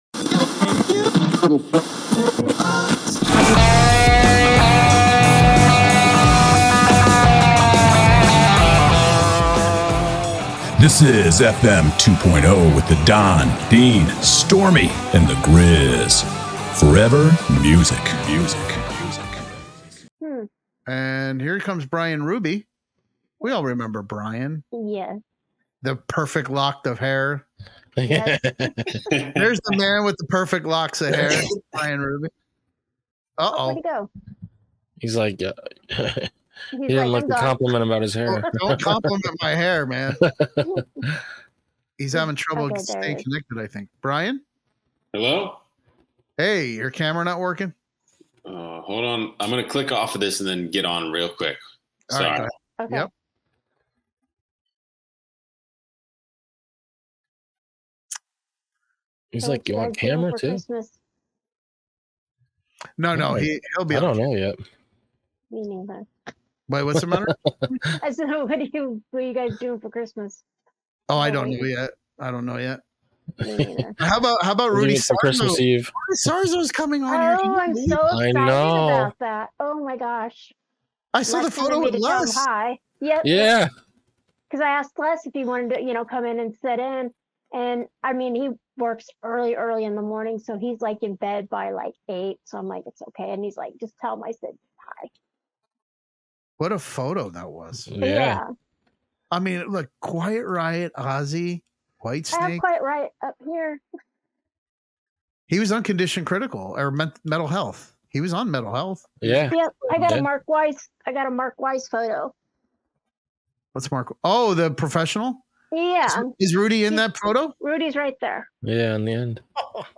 We also featured 2 of his new Christmas songs.